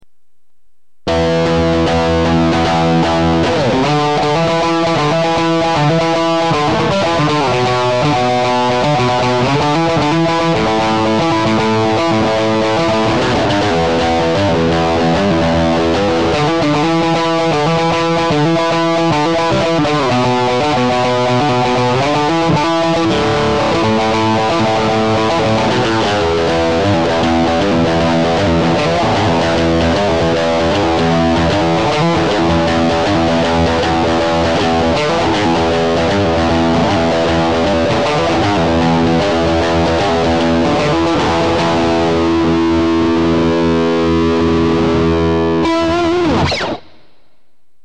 Mozda je malkice preglasno...